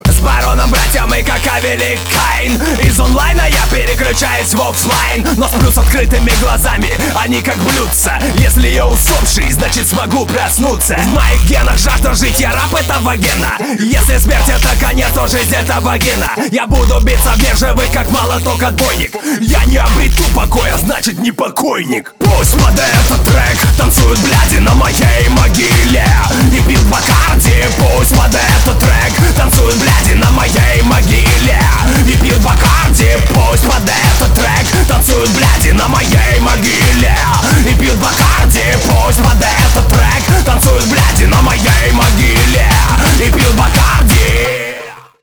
• Качество: 320, Stereo
громкие